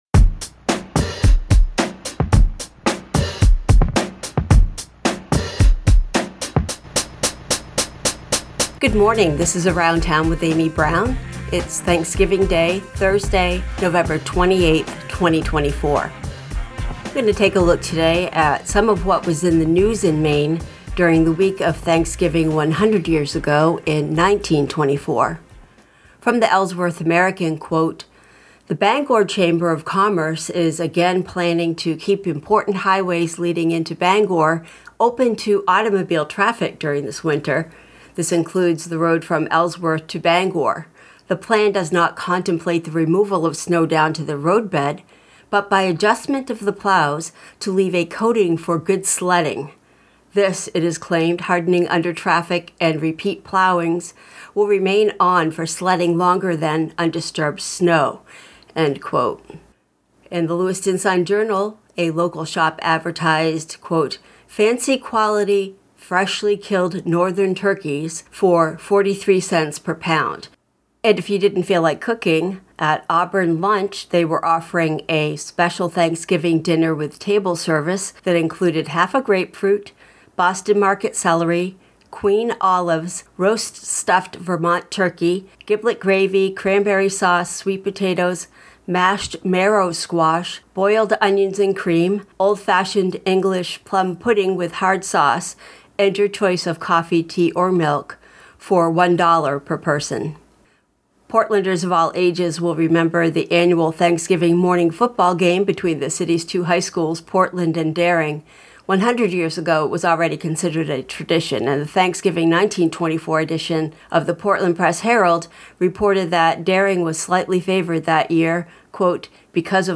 Around Town 11/28/24: Local News, Culture and Events